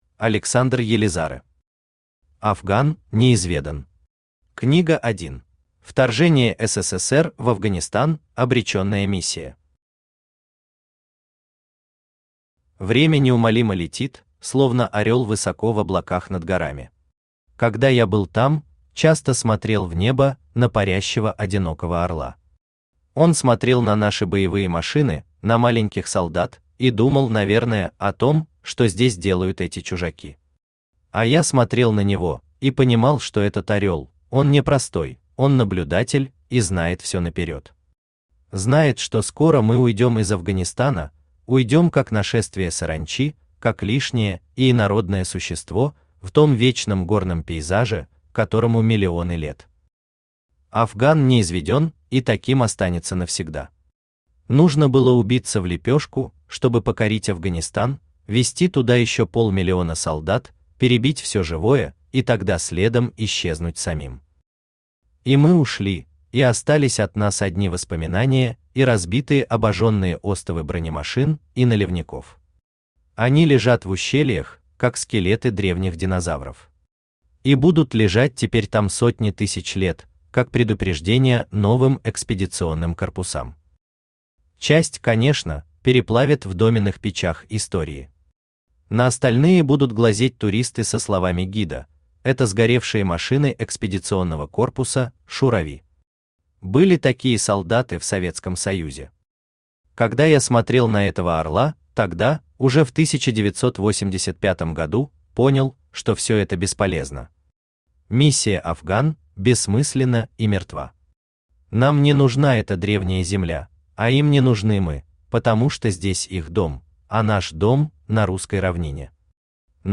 Аудиокнига Афган неизведан. Книга 1 | Библиотека аудиокниг
Aудиокнига Афган неизведан. Книга 1 Автор Александр Елизарэ Читает аудиокнигу Авточтец ЛитРес.